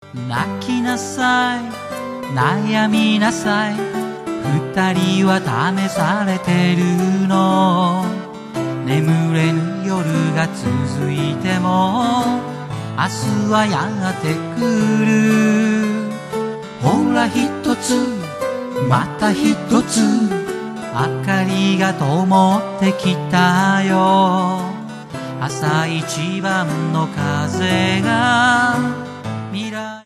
Percussion / Cajon / Cho.
Vo. / A.Guitar
A.Guitar / E.Guitar / Cho.
日本のリズムとロックを融合させてここに成就す！